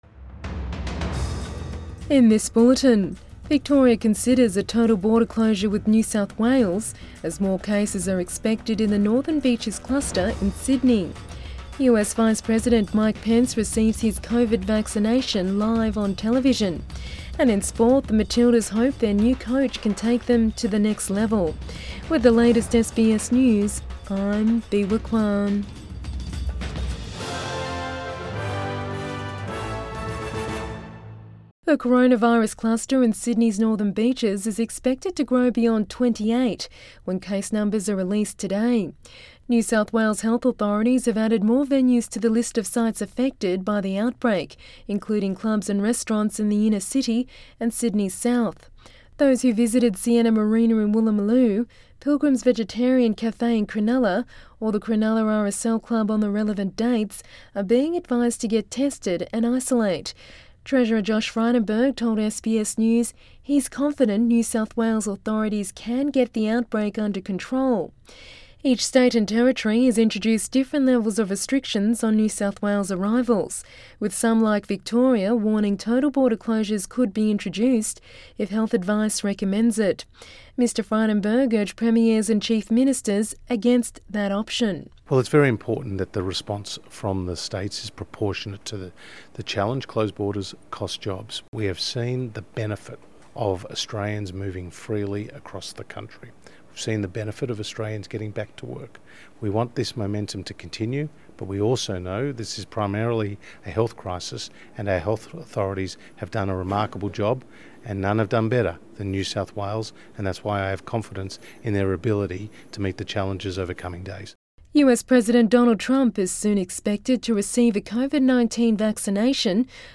AM bulletin 19 December 2020